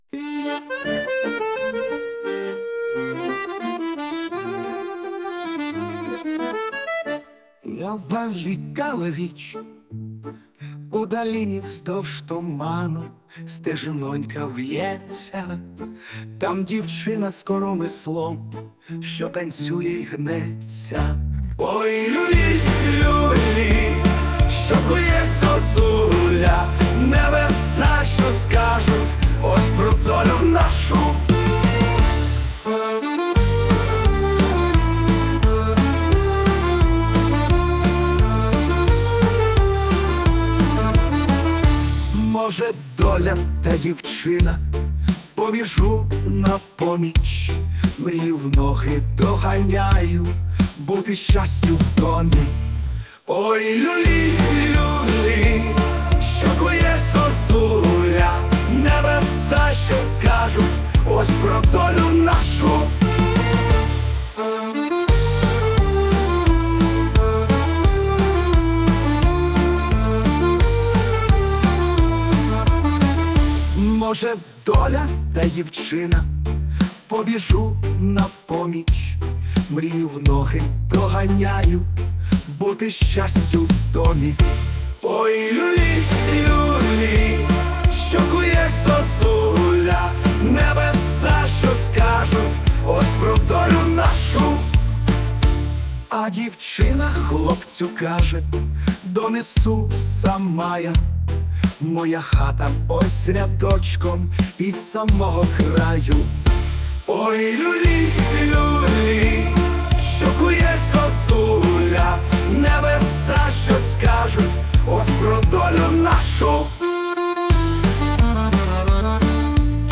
Доле наша… доле… пісня